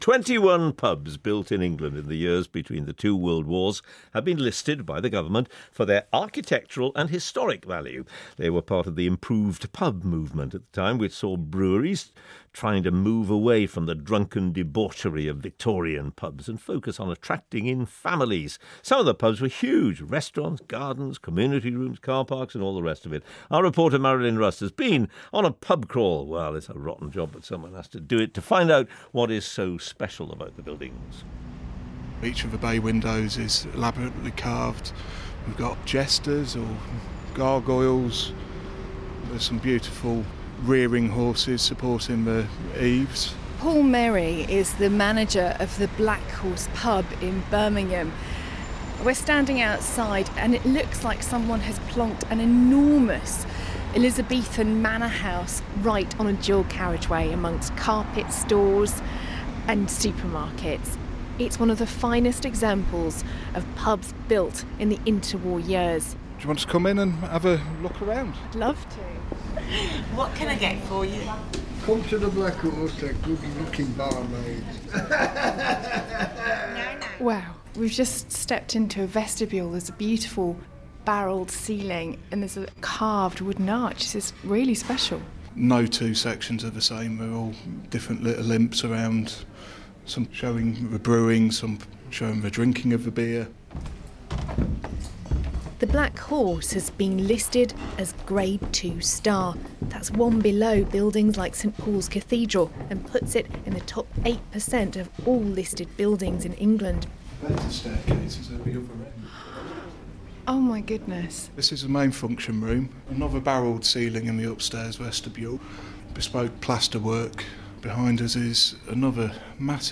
21 pubs built during the inter-war years have been given listed status by the Government, protecting them from re-development. Here is my report for the Today programme